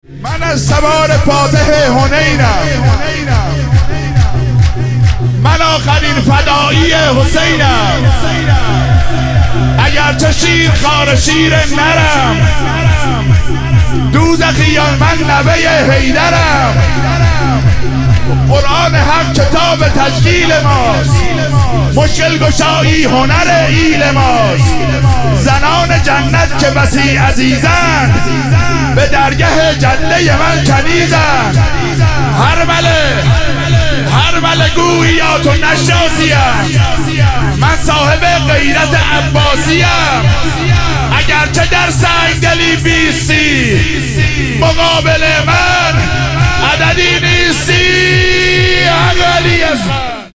ظهوروجود مقدس امام کاظم علیه السلام - شور